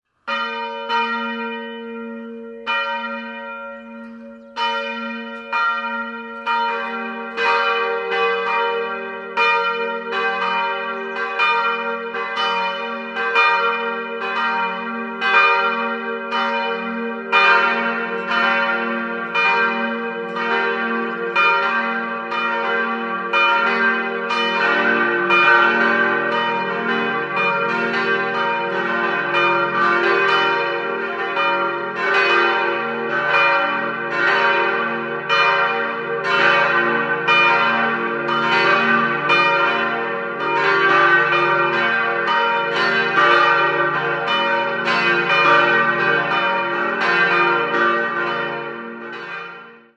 Glocke 1 d' 1.500 kg 140 cm 1970 Rudolf Perner, Passau Glocke 2 f' 800 kg 120 cm 1970 Rudolf Perner, Passau Glocke 3 g' 700 kg 108 cm 1970 Rudolf Perner, Passau Glocke 4 b' 400 kg 90 cm 1970 Rudolf Perner, Passau Quelle: Kath.